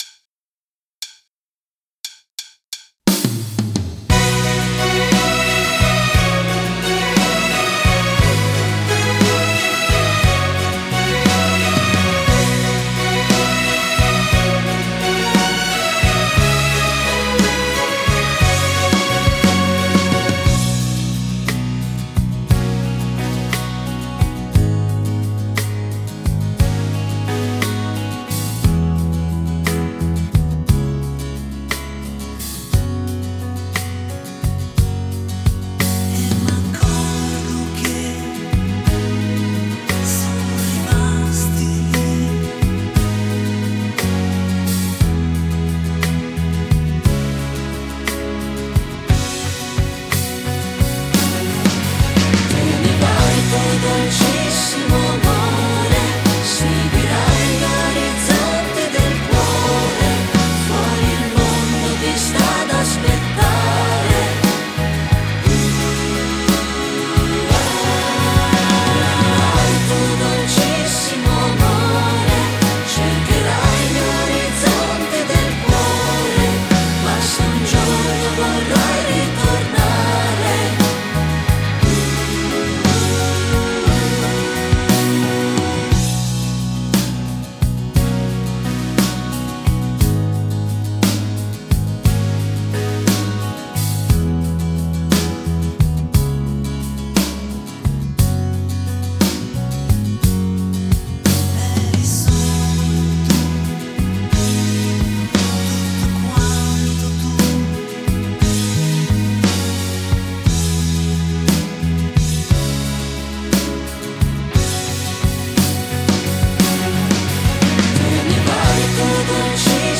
Base musicale con cori